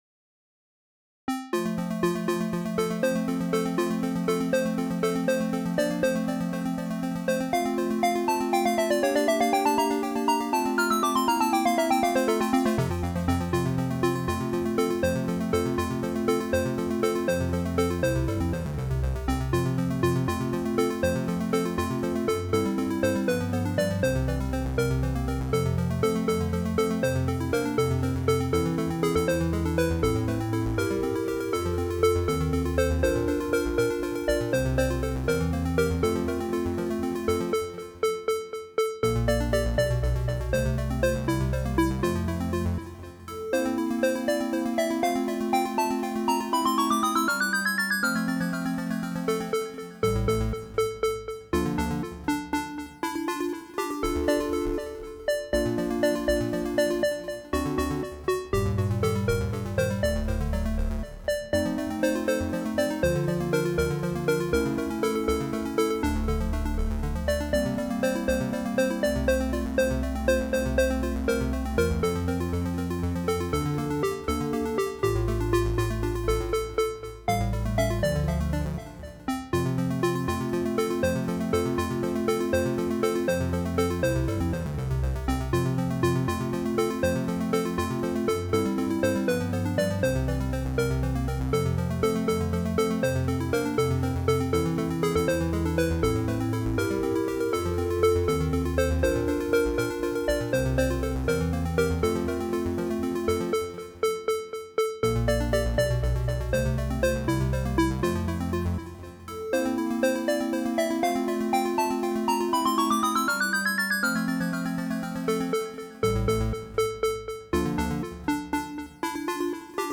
Some cheery march music